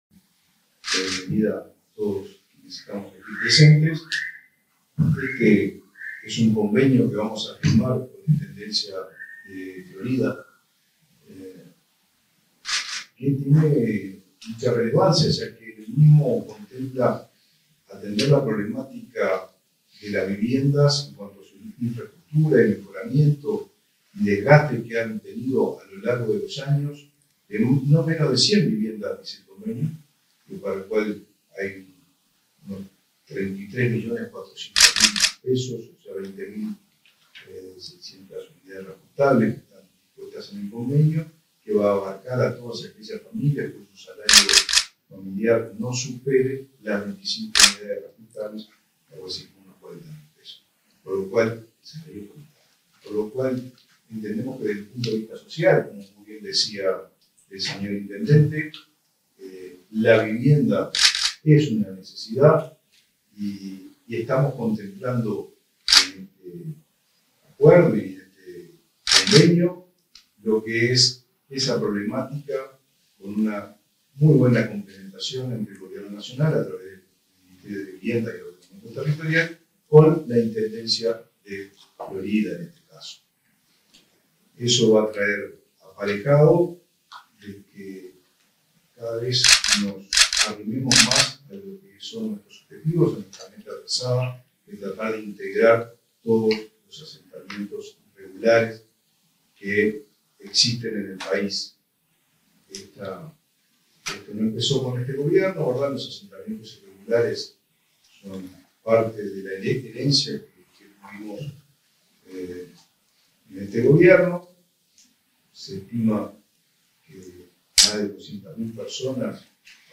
Acto por la firma de convenio entre el MVOT y la Intendencia de Florida
Acto por la firma de convenio entre el MVOT y la Intendencia de Florida 21/11/2023 Compartir Facebook X Copiar enlace WhatsApp LinkedIn El Ministerio de Vivienda y Ordenamiento Territorial (MVOT) y la Intendencia de Florida firmaron, este 21 de noviembre, un convenio para subsidio de mejora de 100 viviendas en ese departamento. Disertaron al respecto el ministro Raúl Lozano y el intendente Guillermo López.